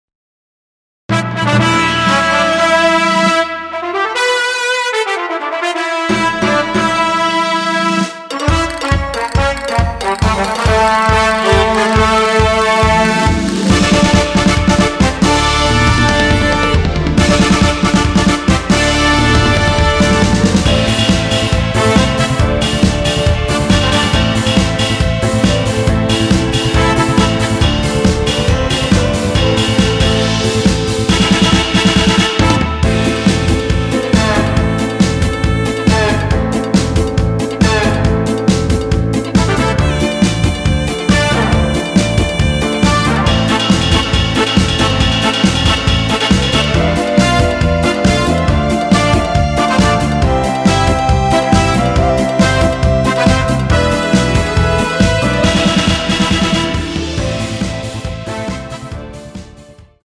원키에서(-6)내린 멜로디 포함된 MR입니다.
Am
멜로디 MR이라고 합니다.
앞부분30초, 뒷부분30초씩 편집해서 올려 드리고 있습니다.
중간에 음이 끈어지고 다시 나오는 이유는